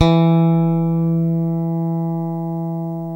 Index of /90_sSampleCDs/Roland L-CDX-01/BS _Jazz Bass/BS _Jazz Basses